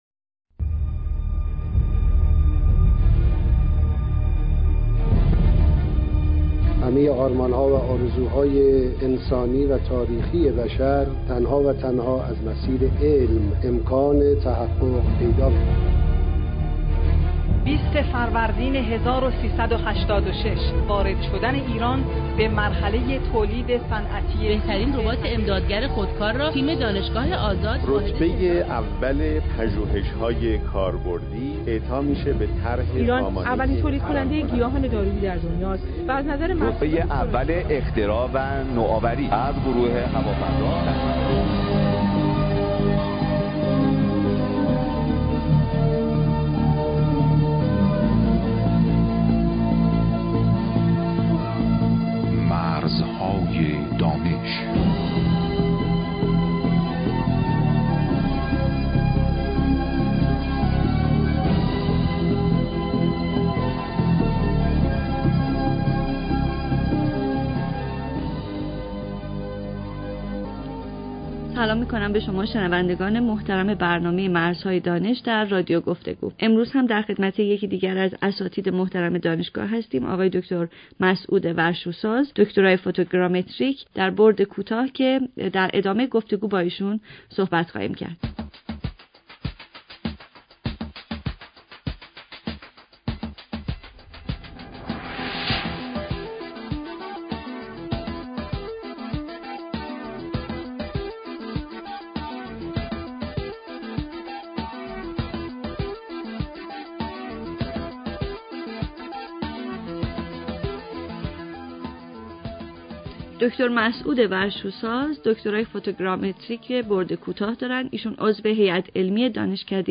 کسانی که مایلند راهکارهای رسیدن به اختراع را به صورت صوتی بشنوند می توانند هر هفته برنامه مرزهای دانش که از رادیو گفتگو پخش می شود دنبال نمایند همچنین در سایت اینترنتی موسسه رشد خلاقیت نوآوران جوان این برنامه ها برای دانلود قرار می گیرد